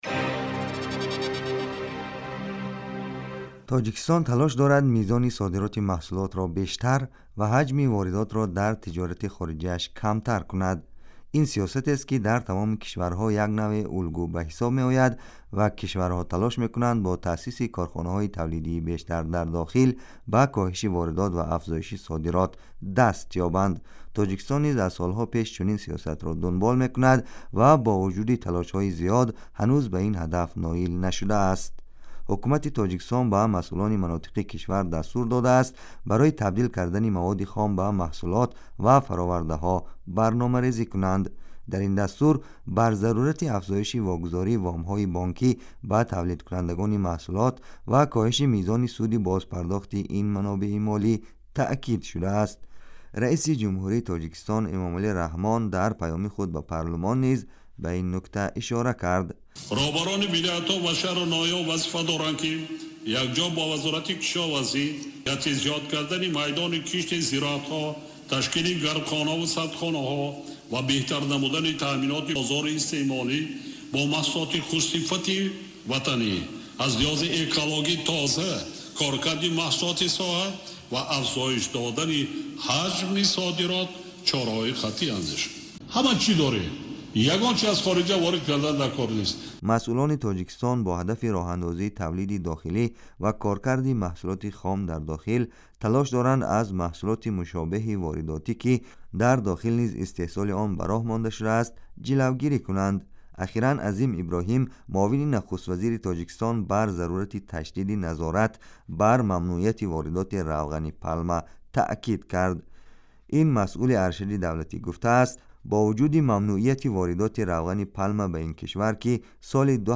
Радио